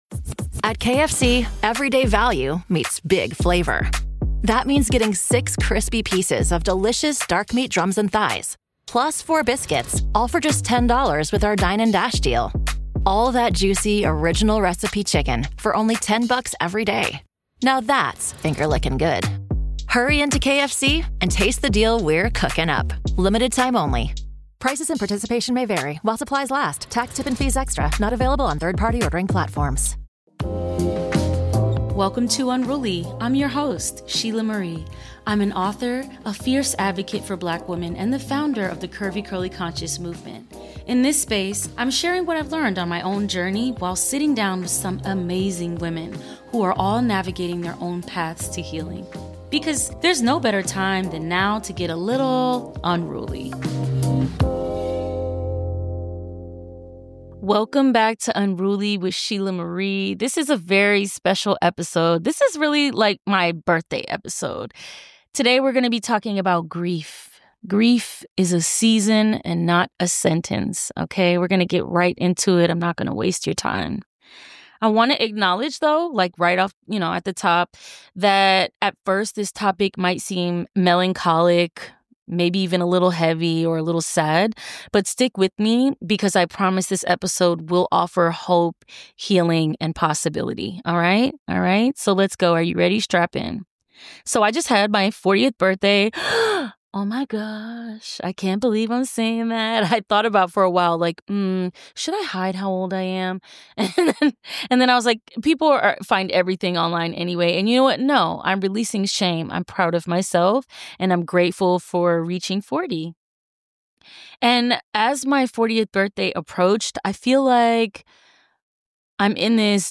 In this intimate solo episode